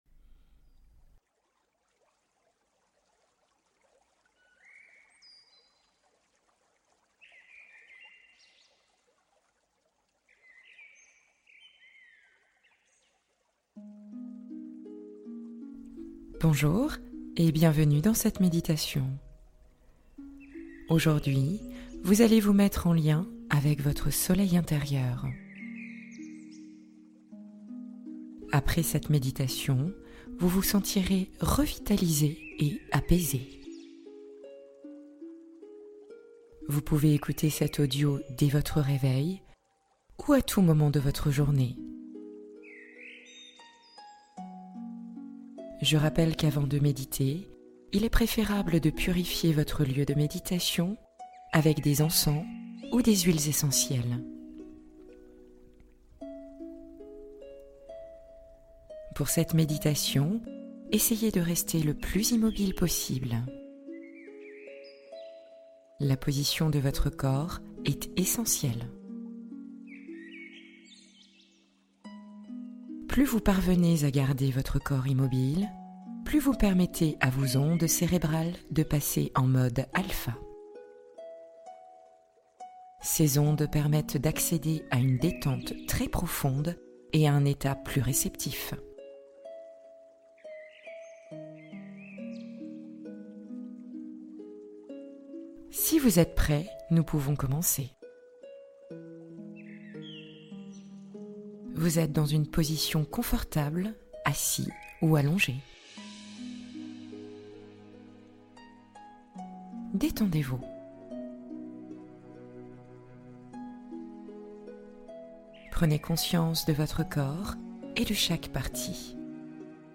Soleil Intérieur : Méditation matinale pour rayonner de présence